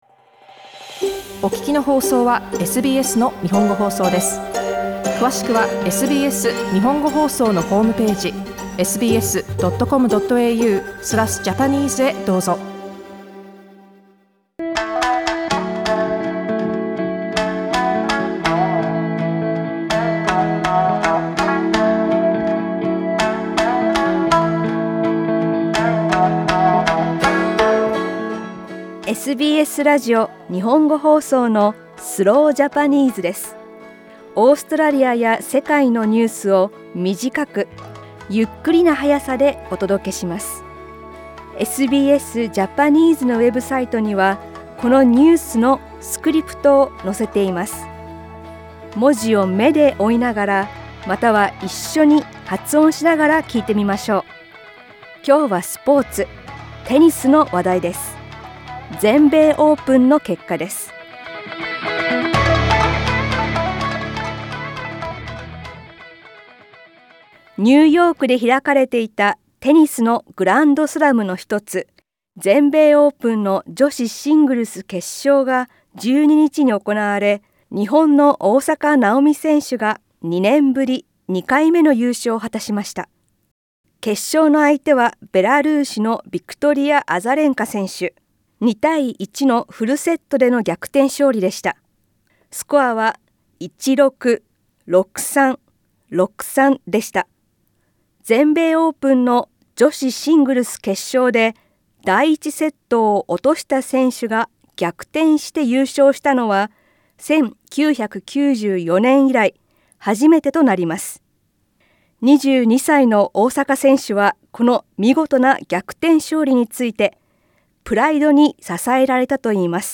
オーストラリアや世界のニュースを短く、ゆっくりの速さでお届けします。